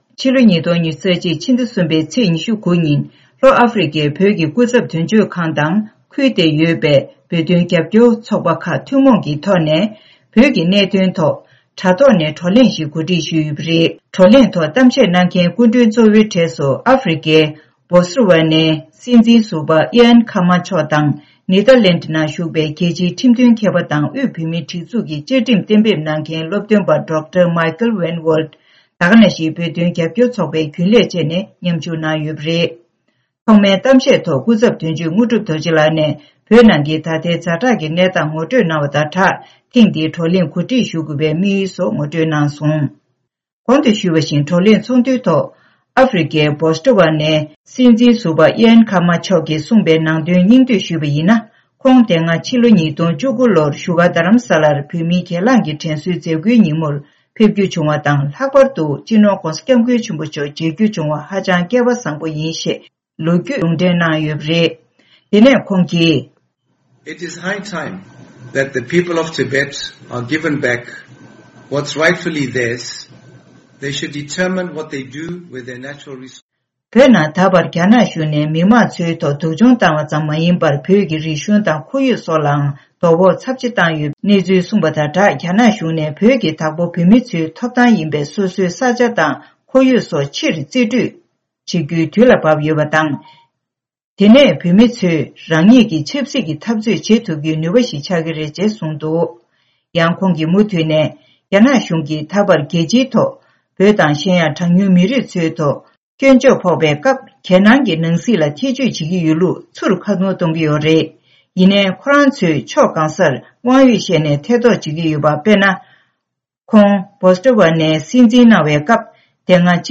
༄༅།།གསར་འགྱུར་དཔྱད་གཏམ་གྱི་ལེ་ཚན་ནང་།